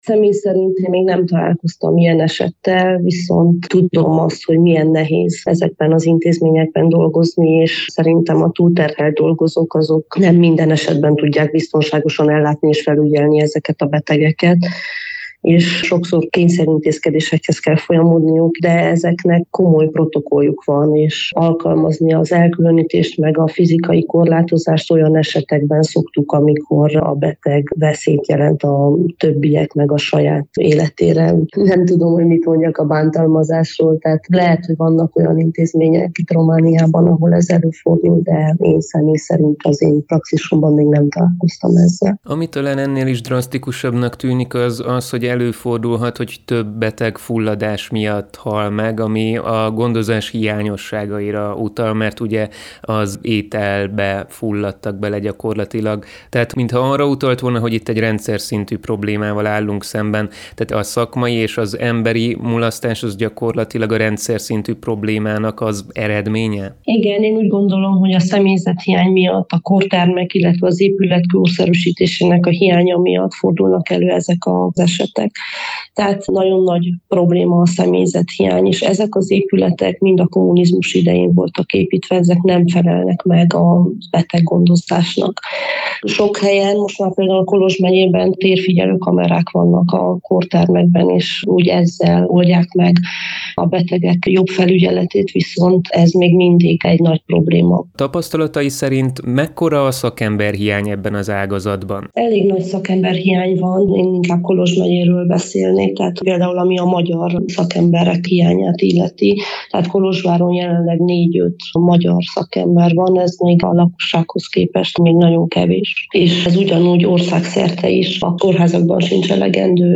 Egy európai jelentés komoly hiányosságokat tárt fel, a rádiónknak nyilatkozó pszichiáter szakorvos szerint ezek egyértelműen rendszerszintű problémára utalnak.